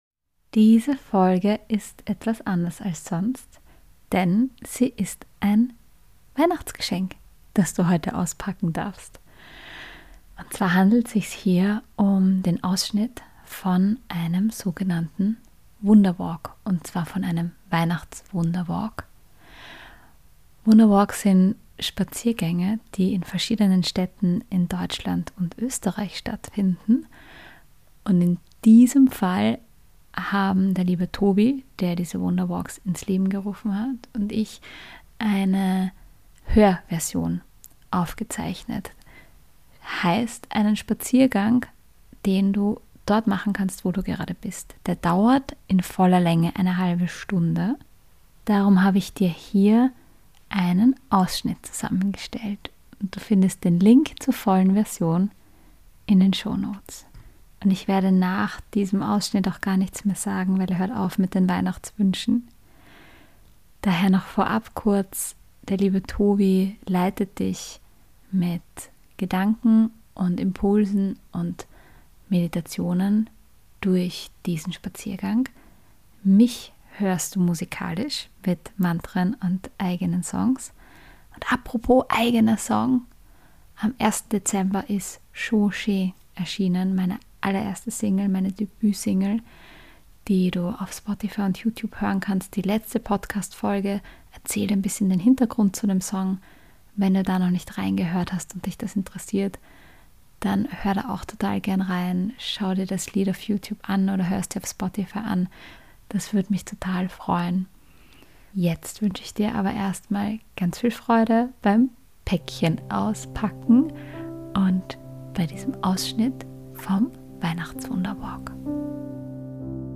Ausschnitt vom WeihnachtsWunderWalk